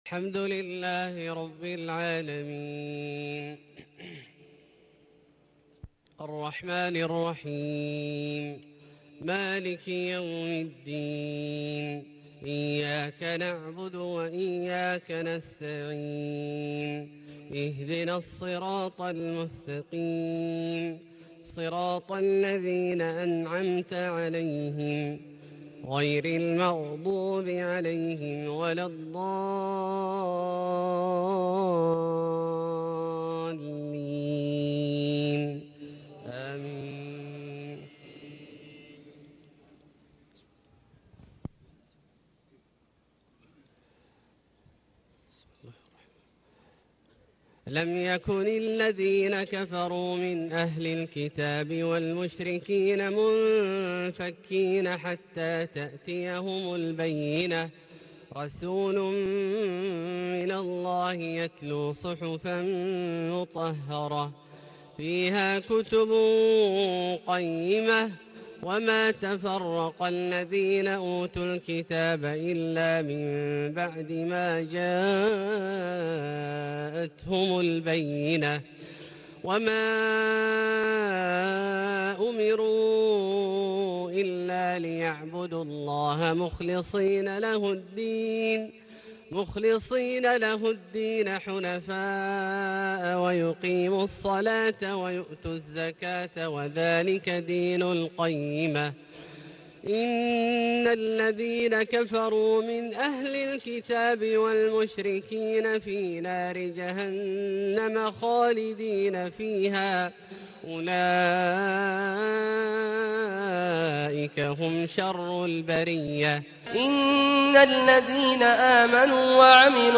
صلاة العشاء 23 محرم 1439هـ | تلاوة لسورتي البينة والعاديات > ١٤٣٩ هـ > الفروض - تلاوات عبدالله الجهني